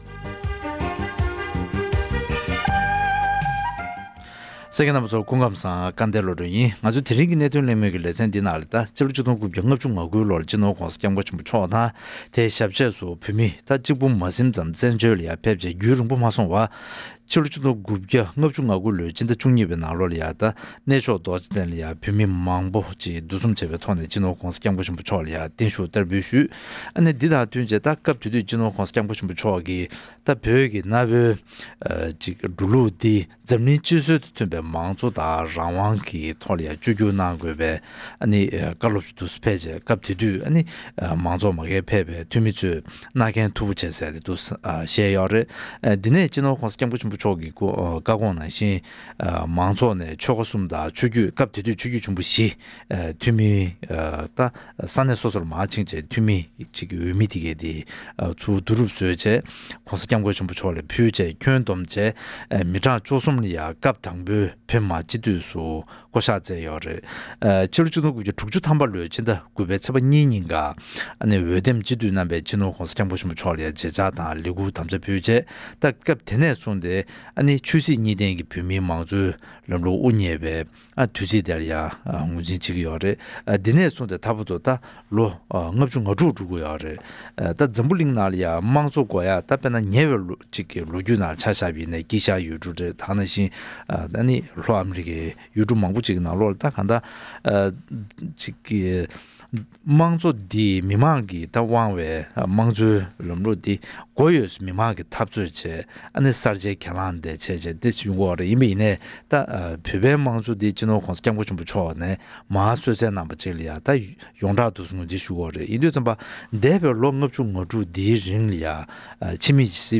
ཆོས་སྲིད་གཉིས་ལྡན་གྱི་བོད་མིའི་དམངས་གཙོའི་འཕེལ་རིམ་དབུ་བརྙེས་ནས་ལོ་ངོ་༥༦འཁོར་ཡོད་པ་དང༌བོད་མིའི་དམངས་གཙོ་འཕེལ་རིམ་སོགས་ཀྱི་སྐོར་གླེང་མོལ།